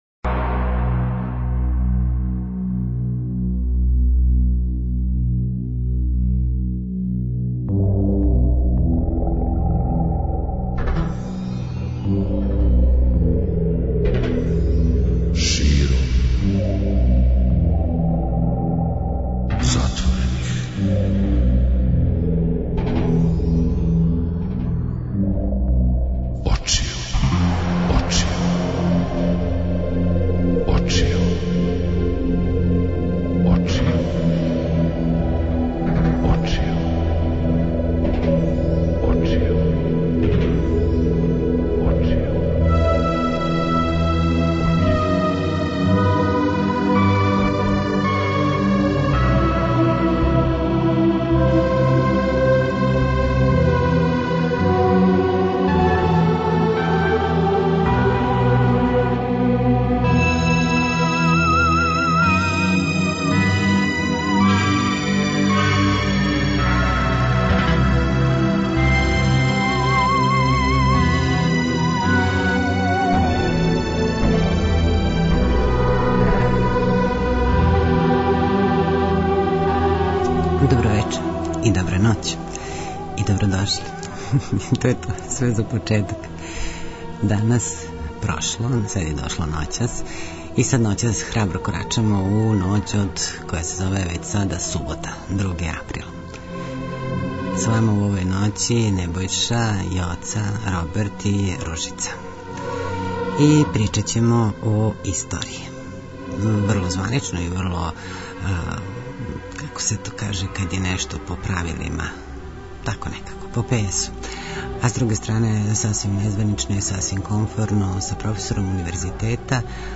Зато ноћас, „широм затворених очију", разговарамо